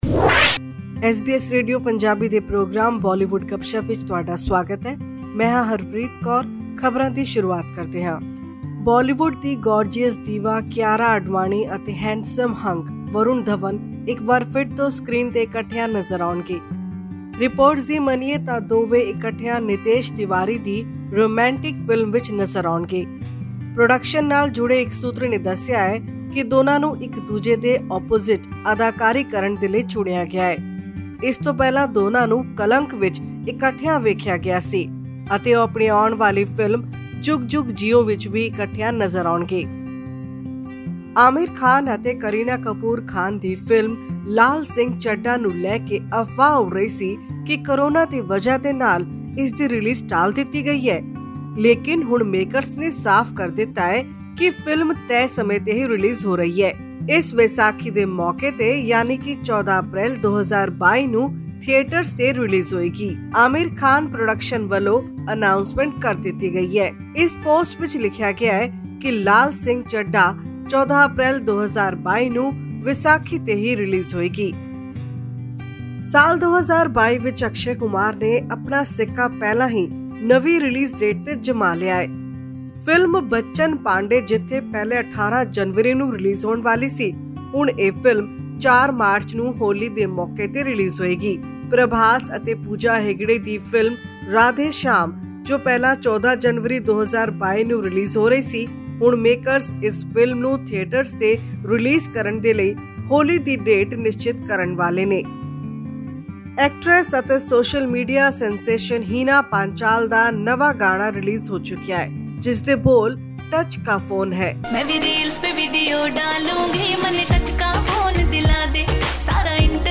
But now the makers have confirmed that it is set to hit theatres on its original release date i.e. 14 April 2022. This and much more in our weekly news bulletin from Bollywood.